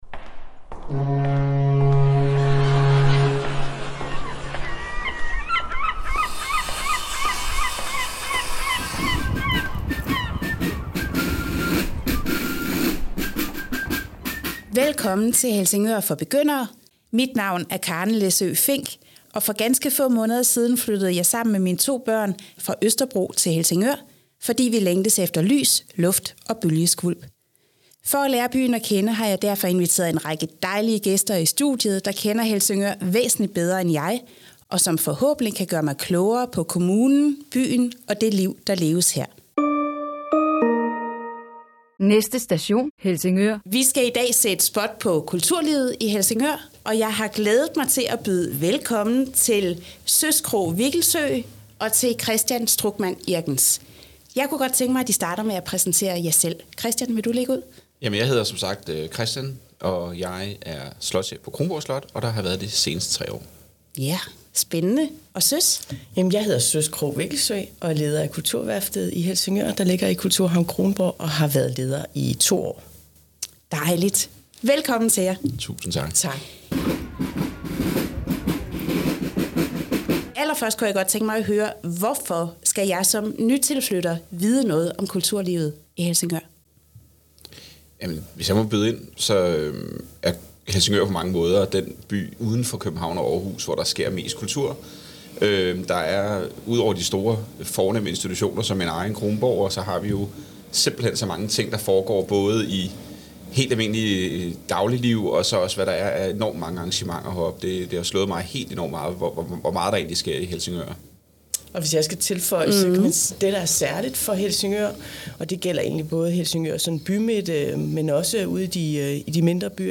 i samtale om kulturlivet i Helsingør. Helsingør er en af de danske byer, hvor der foregår allermest kulturelt. Der er både store koncerter på Kulturhavnen, fællesspisninger, aftenåbent på Kronborg, sanketure i naturen og meget andet godt.